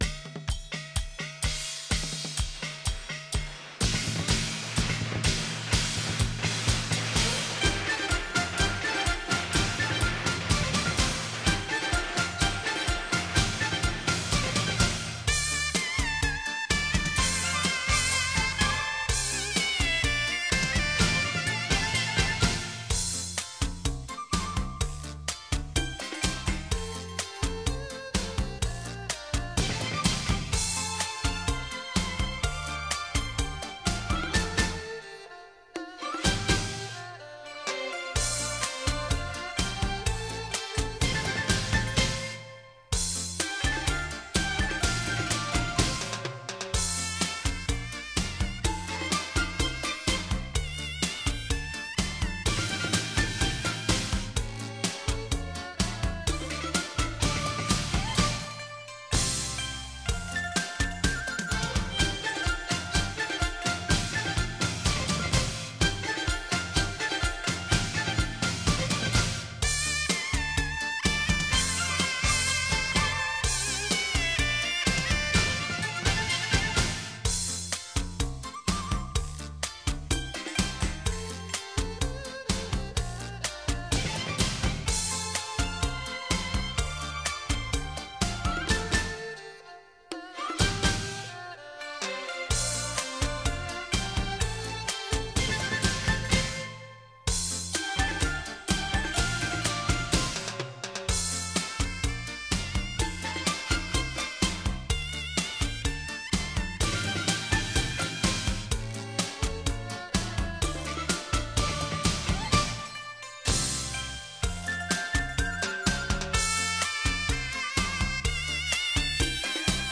[23/1/2009]“祝大家春节快乐”粤语小调《寄语贺年卡》 激动社区，陪你一起慢慢变老！